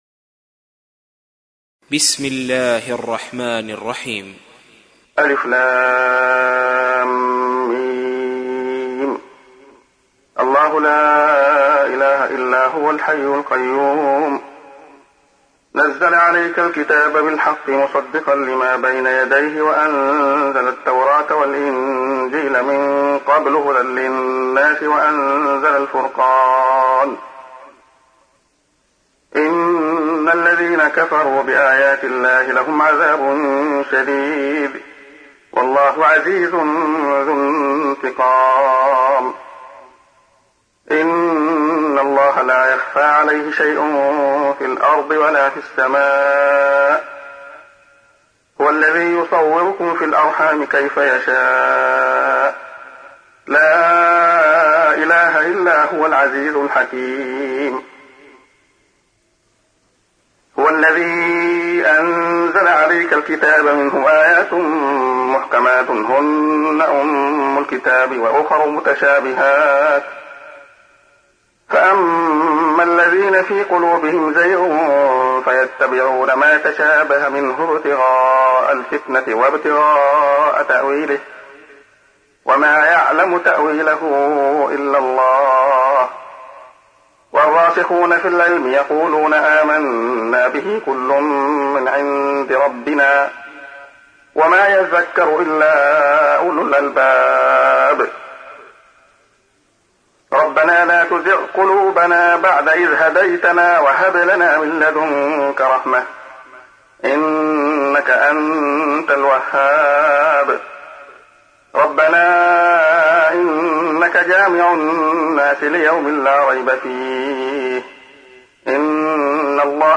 تحميل : 3. سورة آل عمران / القارئ عبد الله خياط / القرآن الكريم / موقع يا حسين